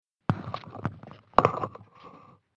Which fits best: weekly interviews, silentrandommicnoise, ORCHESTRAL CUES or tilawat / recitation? silentrandommicnoise